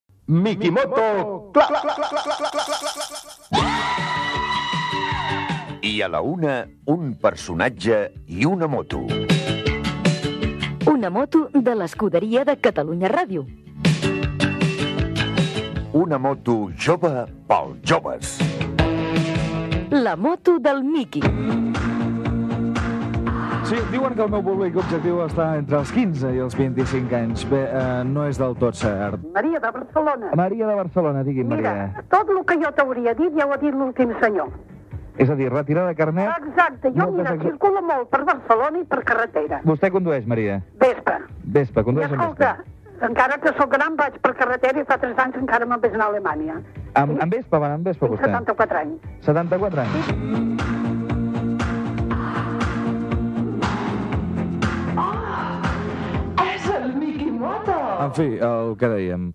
Promoció del programa
Entreteniment